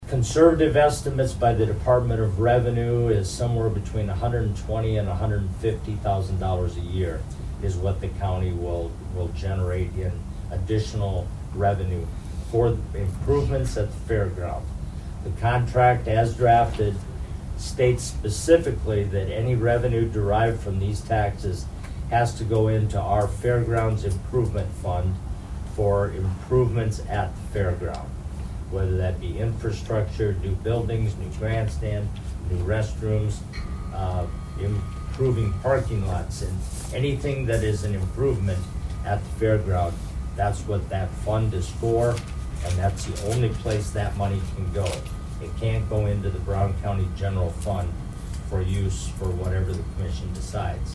Sutton goes over the figures this could generate for the county.